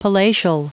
Prononciation du mot palatial en anglais (fichier audio)
Prononciation du mot : palatial